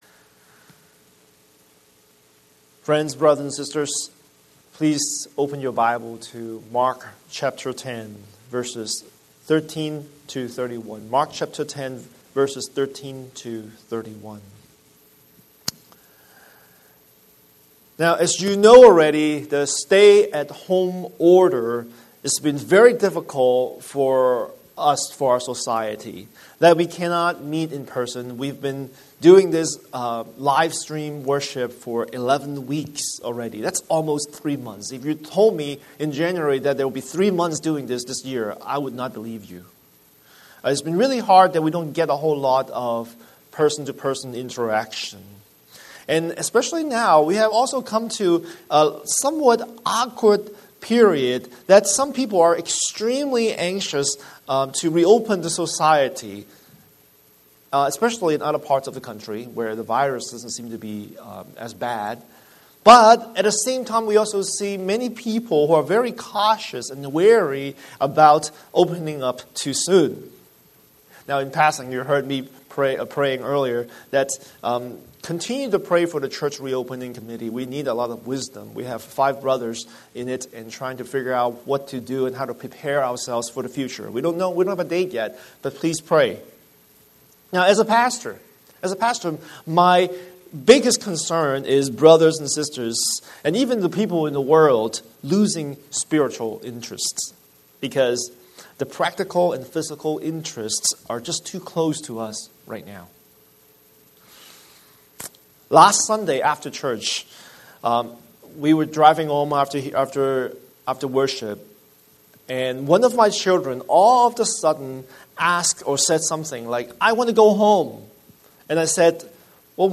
Scripture: Mark 10:13–31 Series: Sunday Sermon